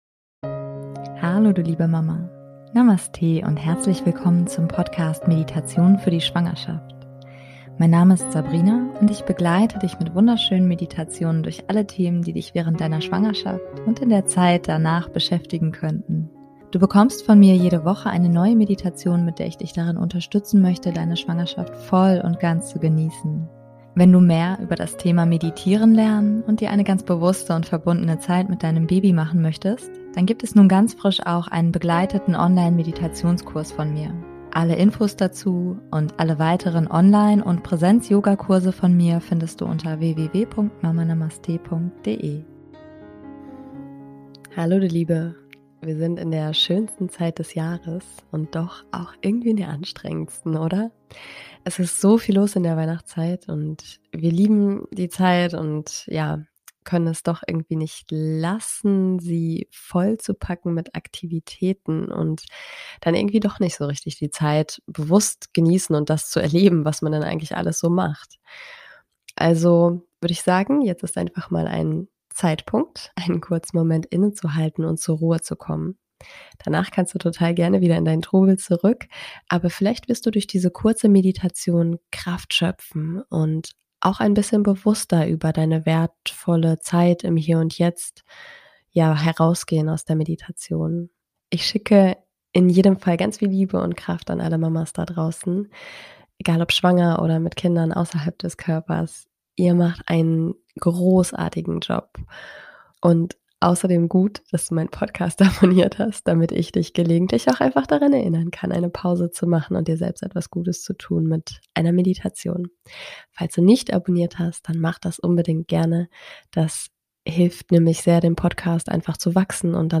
#116 - Entschleunigen und zur Ruhe kommen - Meditation [Für Alle] ~ Meditationen für die Schwangerschaft und Geburt - mama.namaste Podcast